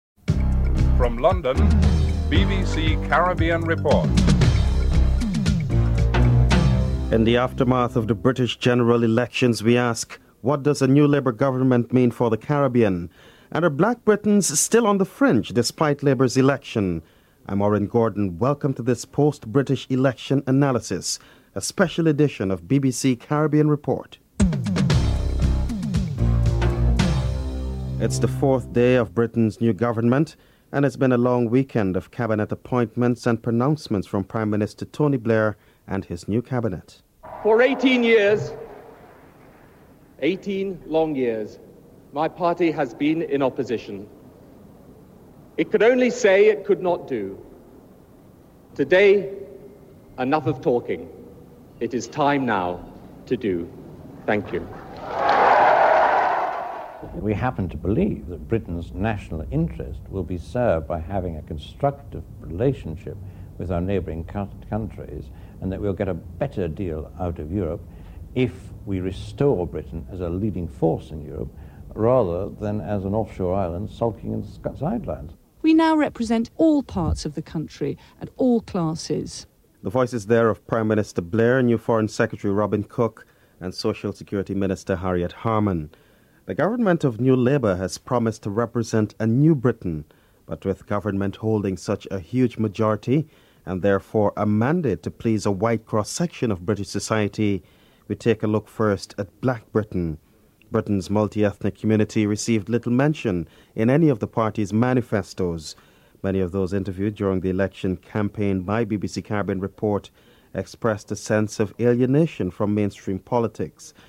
Professor of Sociology, Stuart Hall is interviewed (07:38-09:48)